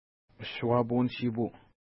Pronunciation: əʃwa:pu:n-ʃi:pu: